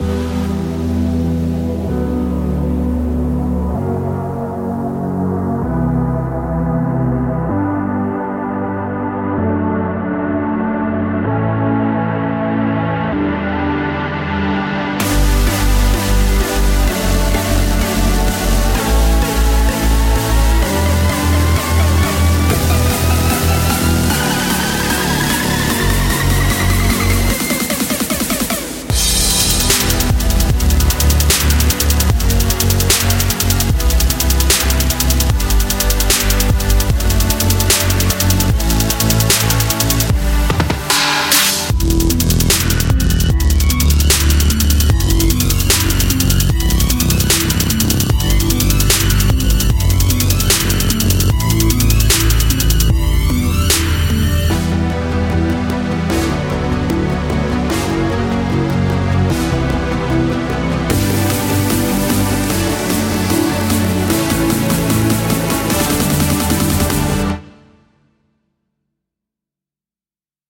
一个EDM银行，它将为您带来灵感。
重载主音和合成器，伴有一些低音！